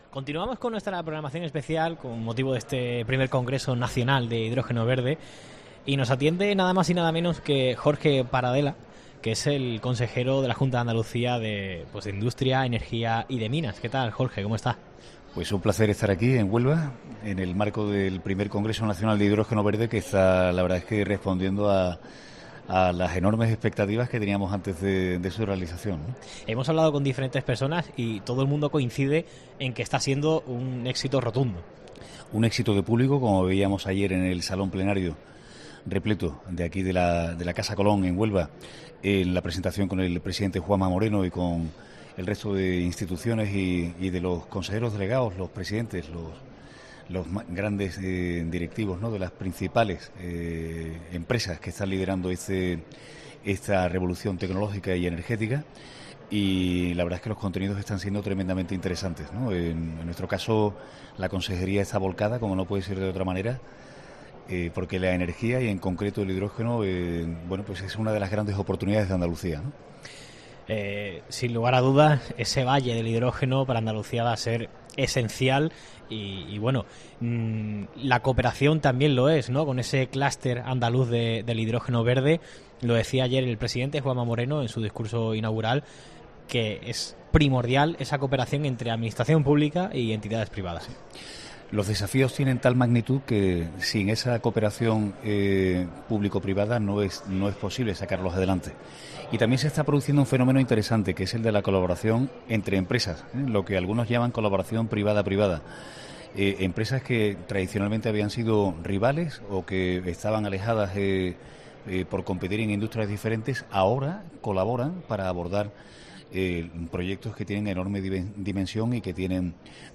Entrevista completa a Jorge Paradela, consejero de Industria, Energía y Minas de la Junta